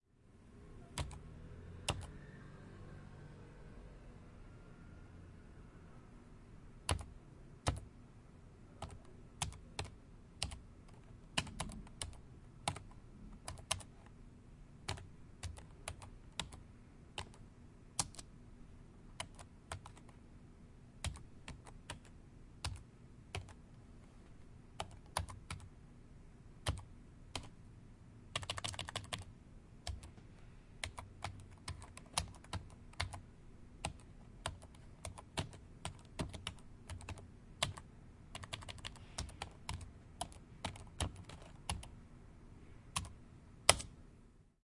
Office » KEYBOARD
描述：Son d’un clavier d’ordinateur. Son enregistré avec un ZOOM H4N Pro. Sound of a computer keyboard. Sound recorded with a ZOOM H4N Pro.
标签： typewriter type typing computer keyboard keys office
声道立体声